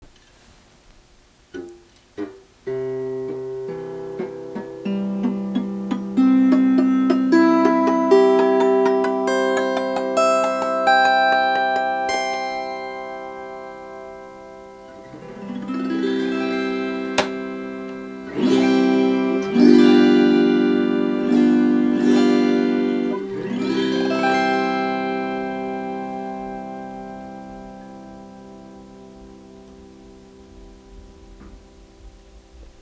Alle Saiten sind frei schwingend.
Gestimmt-C-Dur.mp3